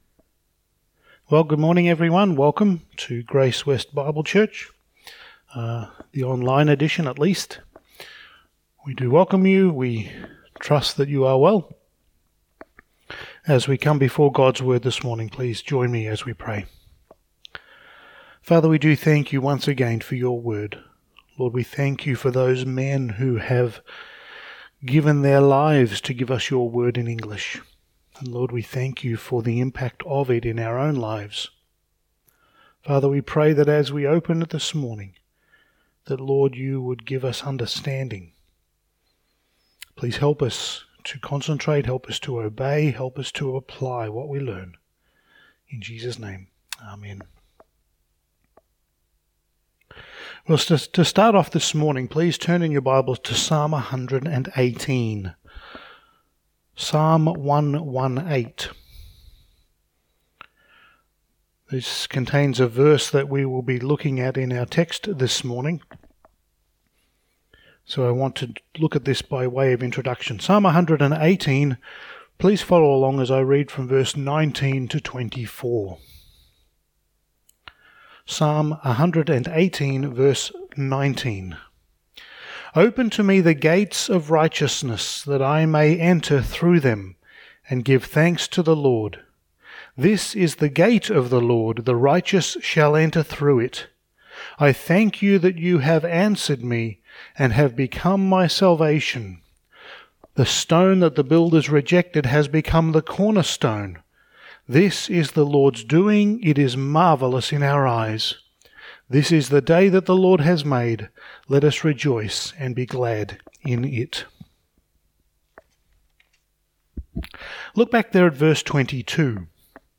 Acts 4:1-22 Service Type: Sunday Morning « Studies in the Book of Acts Series 09